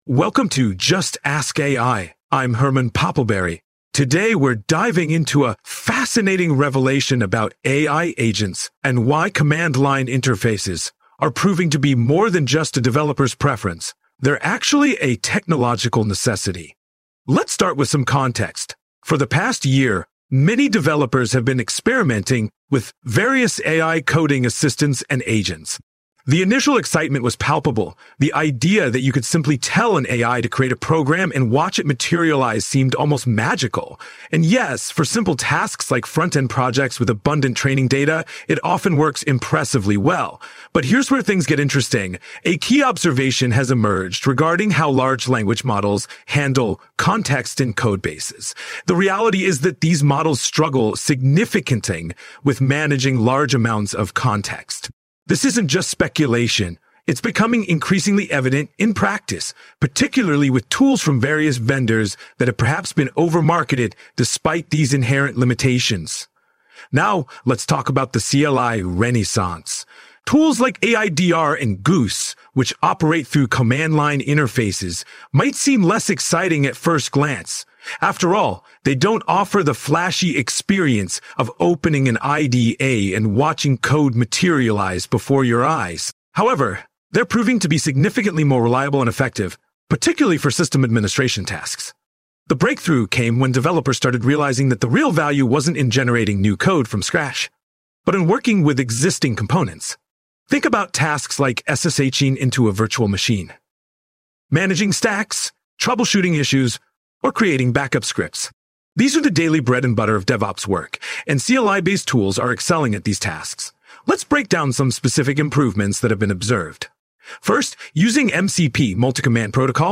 AI-Generated Content: This podcast is created using AI personas.